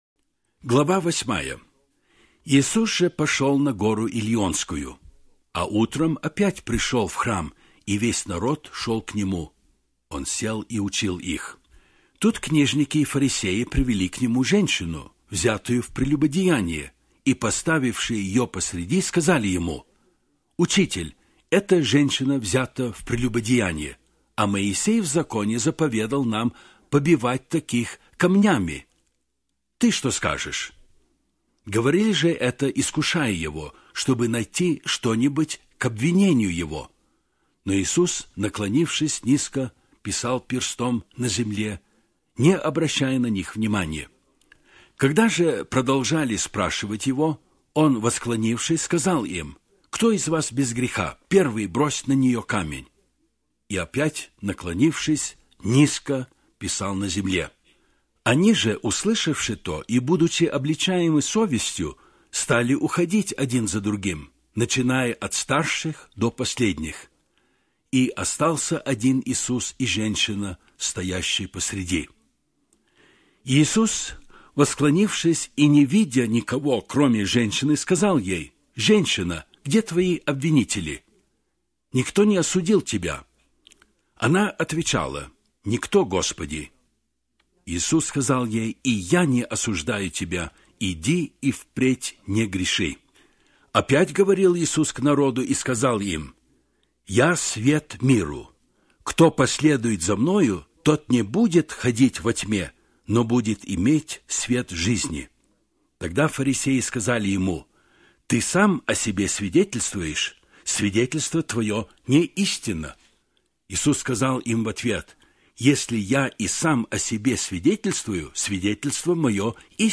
Аудио Библия